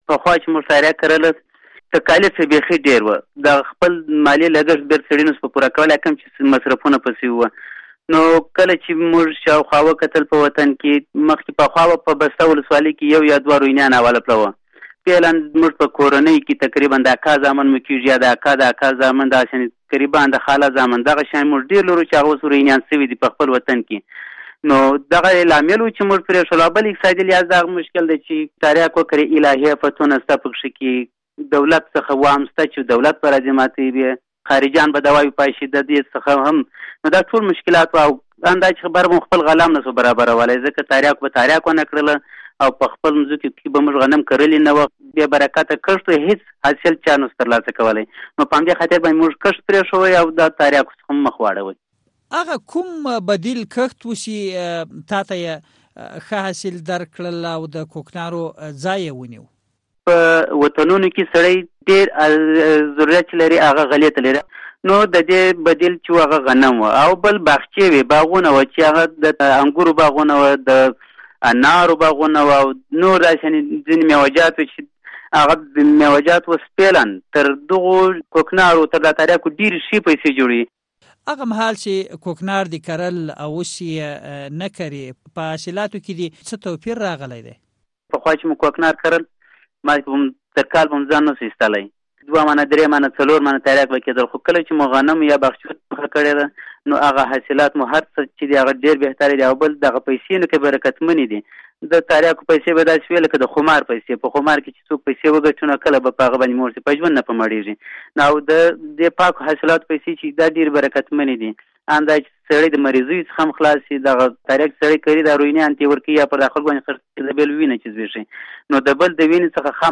په دې اړه مې له نوموړي سره مرکه کړې او په پیل کې مې پوښتلی چې ولې یي په خپلو ځمکو کې کوکنار نه دي کرلي؟